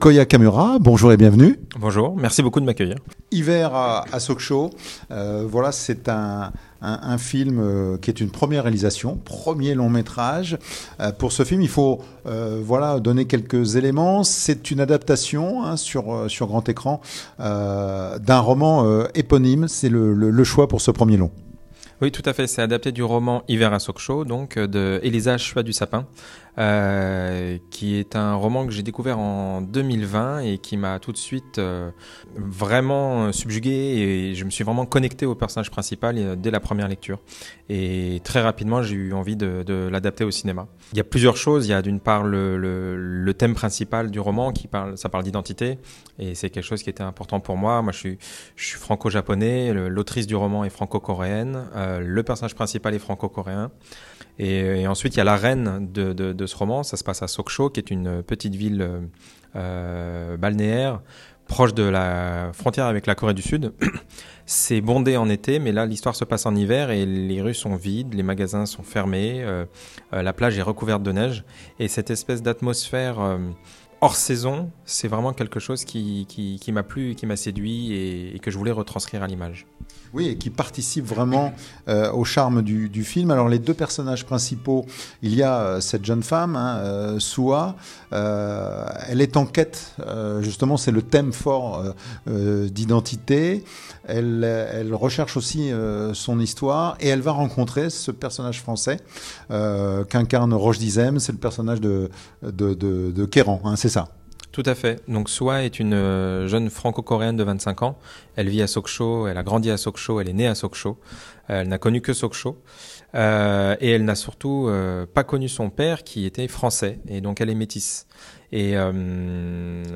dans le cadre du festival « À vous de voir » de Saint-Egrève